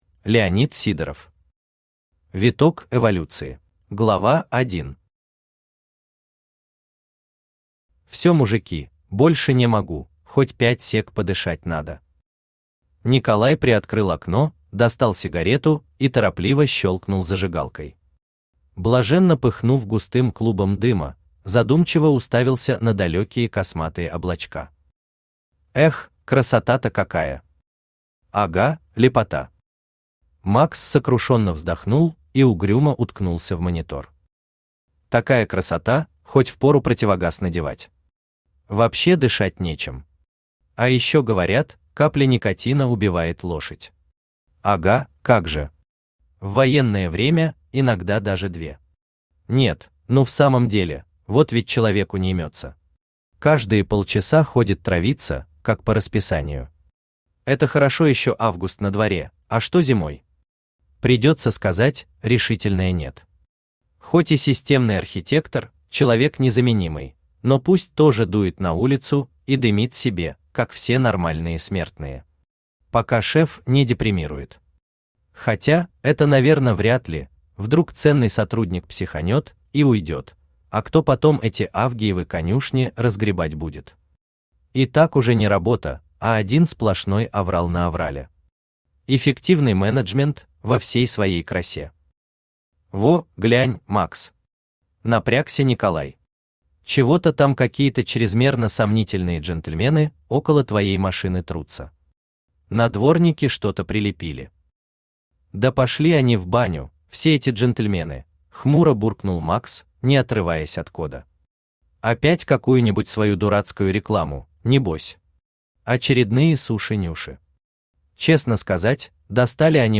Аудиокнига Виток эволюции | Библиотека аудиокниг
Aудиокнига Виток эволюции Автор Леонид Сидоров Читает аудиокнигу Авточтец ЛитРес.